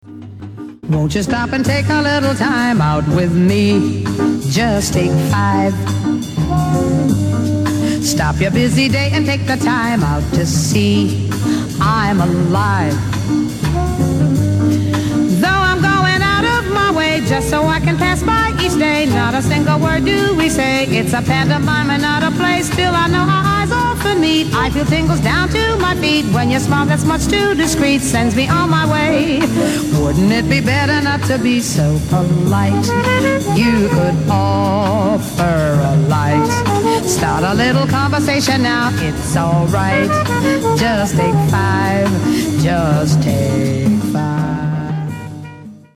was included on the live recording release